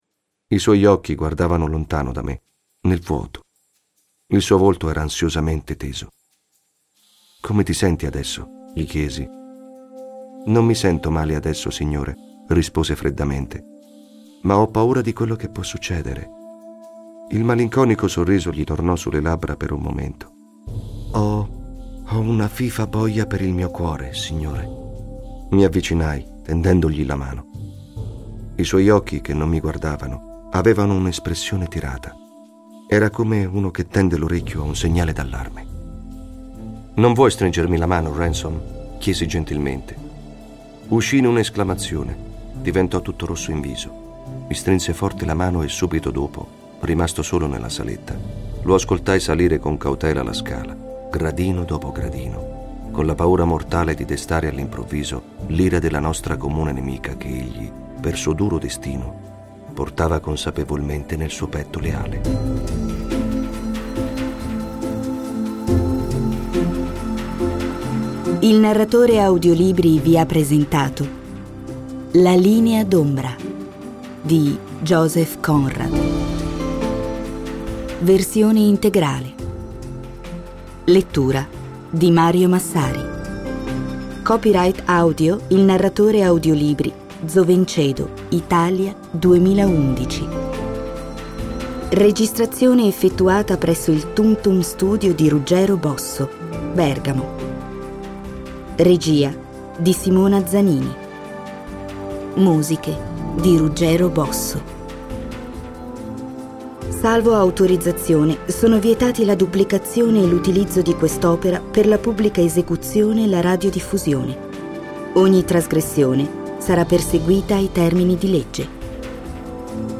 Audiolibro La Linea D'Ombra - TUMTUM Studio
TUMTUM Studio realizza la registrazione de La linea D'ombra, di Joseph Conrad per il Narratore Audiolibri.